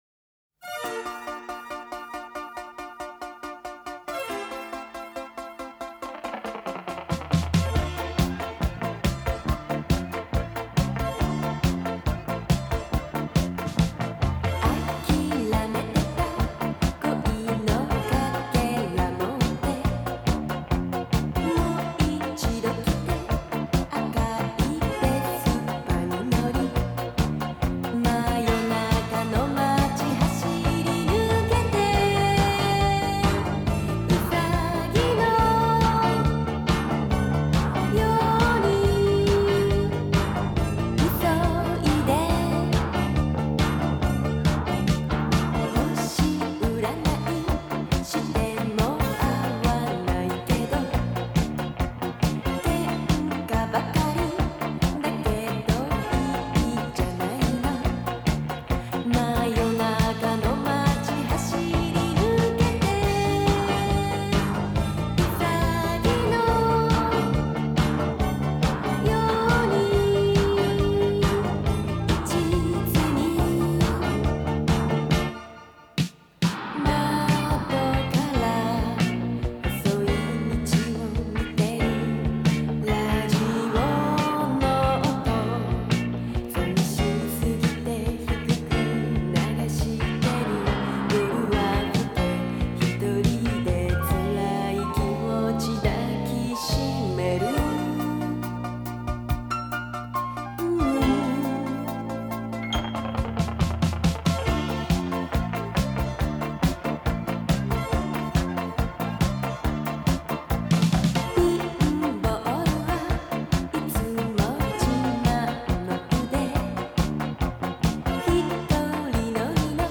"Bound By Books" is hosted by tweens and teens, Fa...